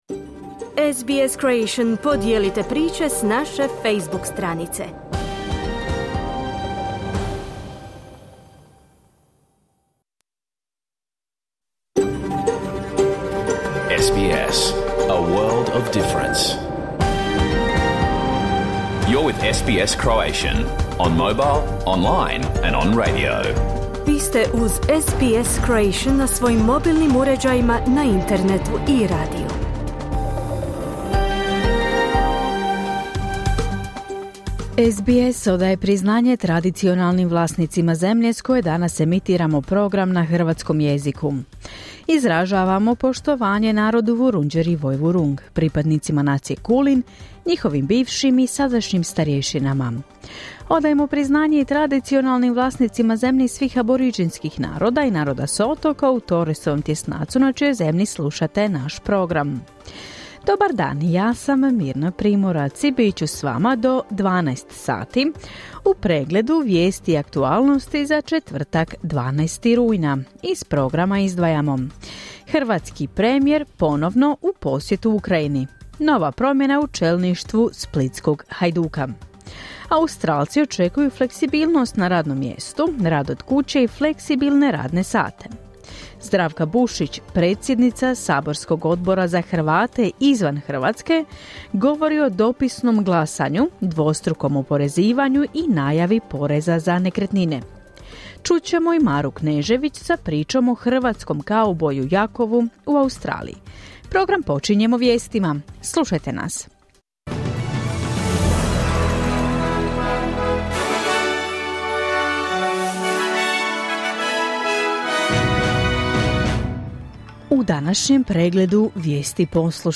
Vijesti i aktualne teme iz Australije, Hrvatske i ostatka svijeta. Program je emitiran uživo na radiju SBS1 u četvrtak, 12. rujna, u 11 sati po istočnoaustralskom vremenu.